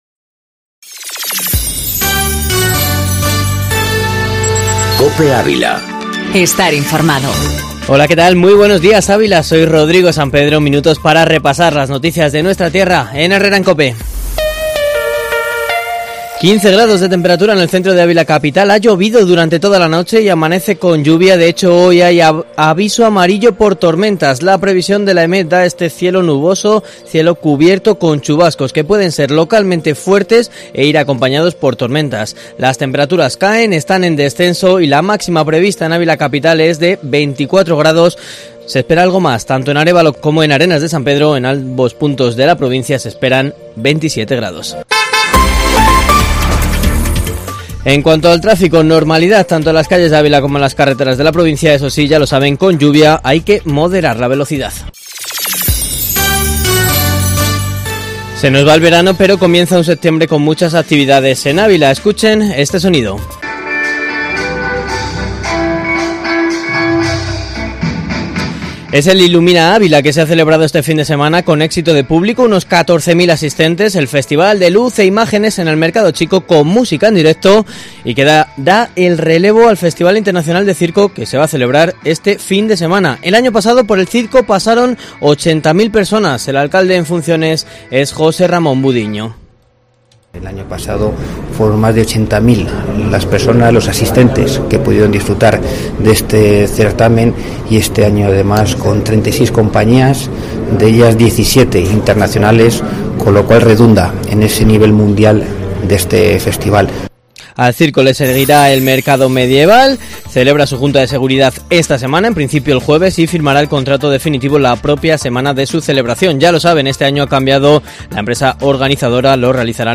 AUDIO: Informativo matinal Herrera en COPE Ávila 26/08/2019